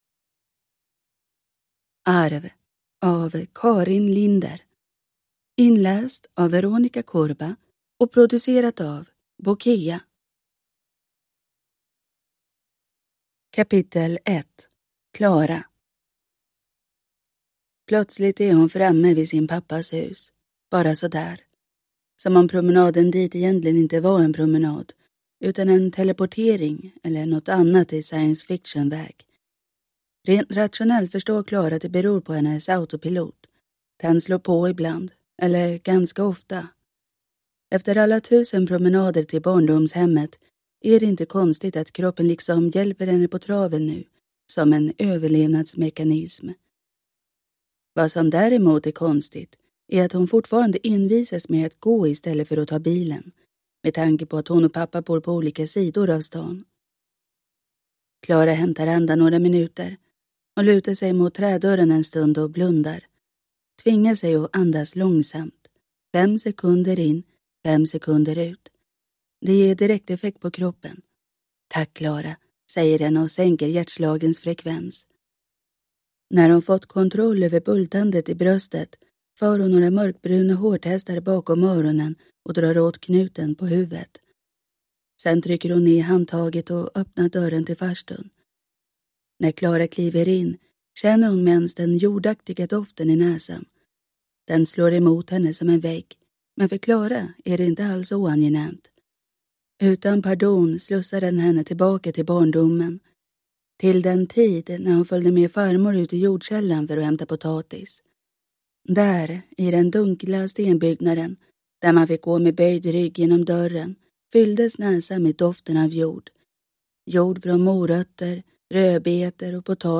Arv – Ljudbok